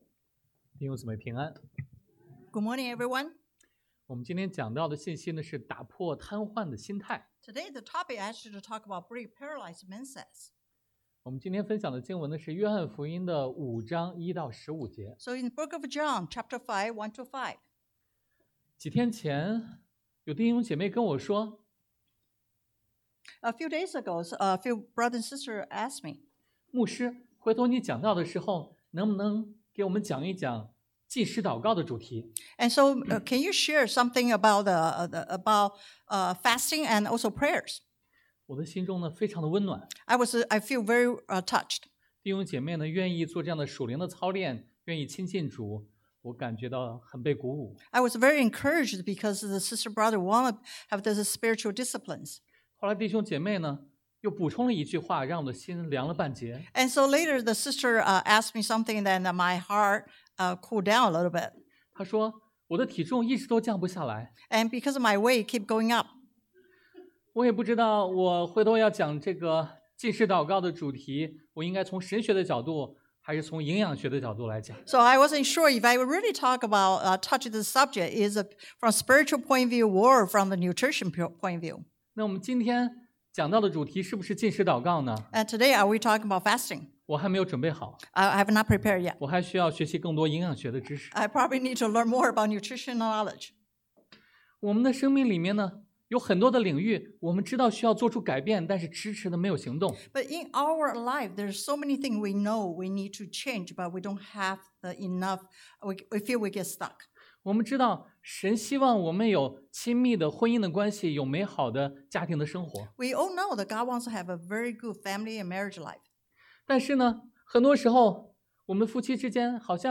Passage: 约翰福音 John 5:1-15 Service Type: Sunday AM Identifying Paralyzed Mindsets 识别瘫痪心态 Jesus’ Healing Power 耶稣的医治能力 The Call to Action 行动的呼召